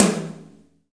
Index of /90_sSampleCDs/Roland - Rhythm Section/KIT_Drum Kits 8/KIT_Reverb Kit
TOM GRINDE07.wav